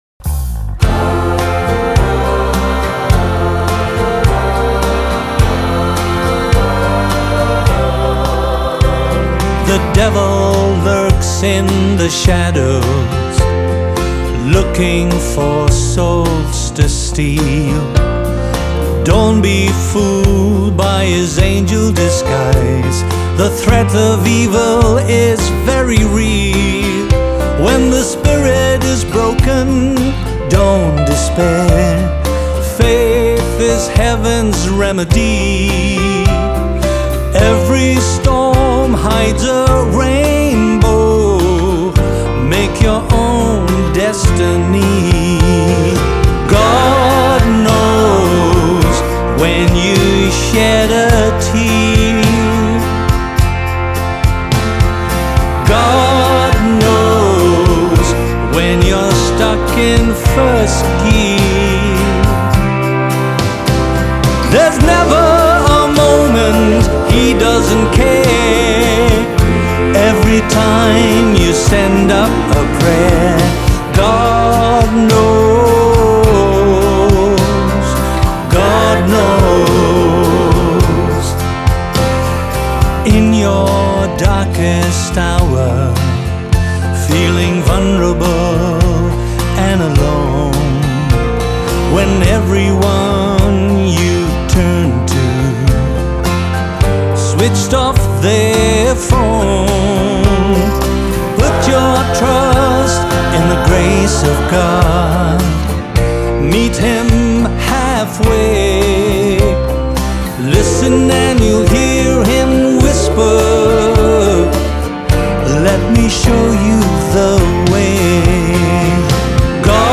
is a gospel song about just that.